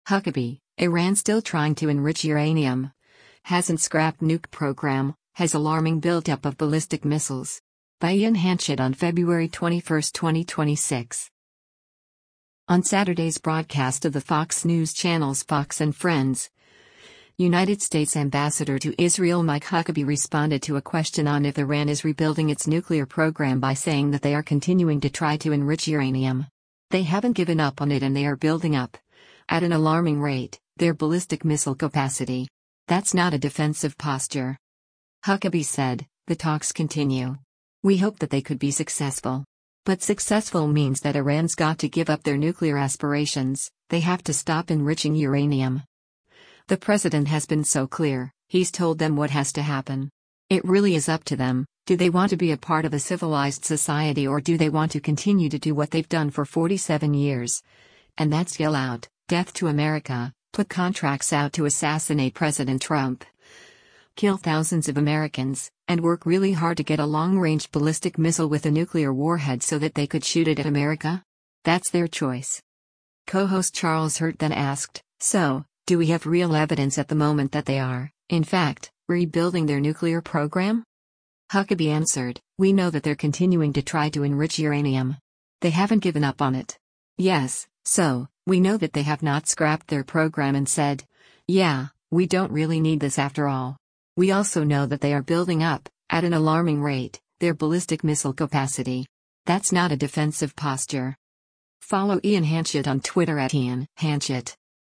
On Saturday’s broadcast of the Fox News Channel’s “Fox & Friends,” United States Ambassador to Israel Mike Huckabee responded to a question on if Iran is rebuilding its nuclear program by saying that they are “continuing to try to enrich uranium. They haven’t given up on it” and “they are building up, at an alarming rate, their ballistic missile capacity. That’s not a defensive posture.”
Co-host Charles Hurt then asked, “So, do we have real evidence at the moment that they are, in fact, rebuilding their nuclear program?”